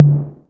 076 - Tom-1.wav